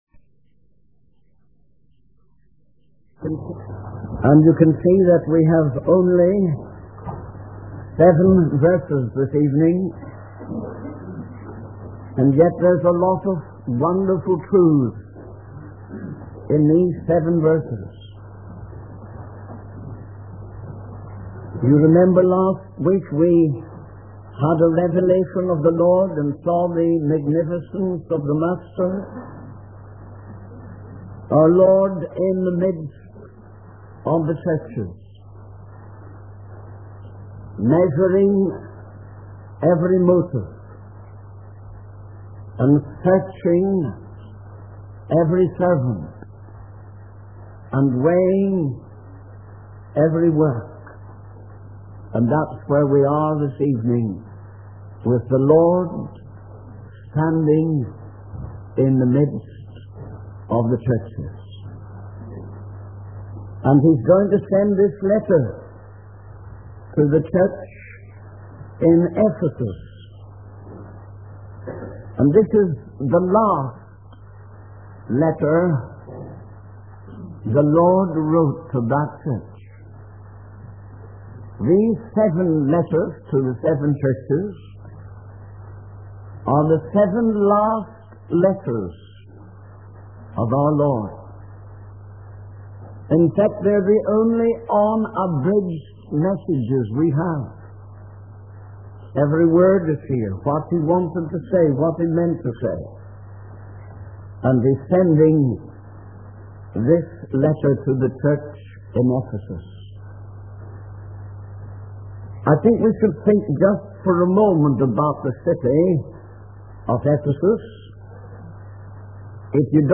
In this sermon, the preacher discusses the importance of remembering and returning to one's first love for God. He emphasizes that it is possible to have many good qualities and be committed to serving God, but still lack a deep love for Him. The preacher urges the listeners to reflect on their initial love for Christ and to repent and restart their relationship with Him.